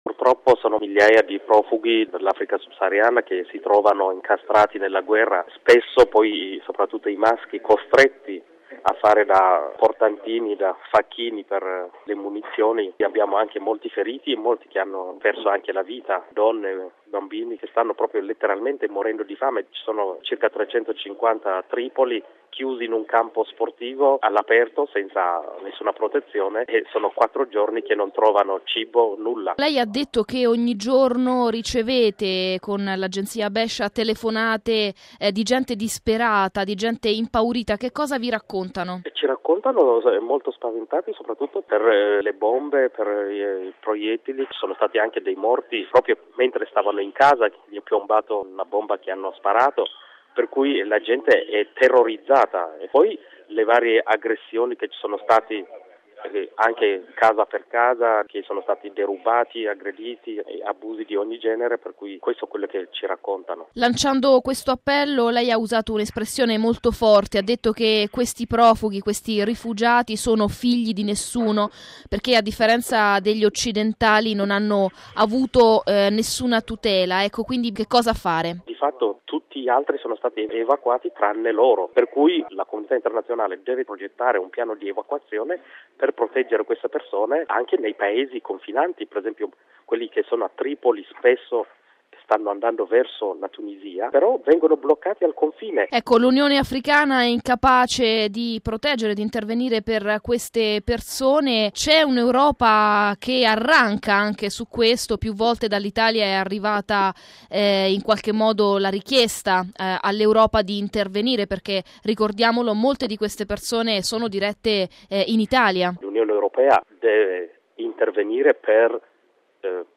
Bollettino Radiogiornale del 24/08/2014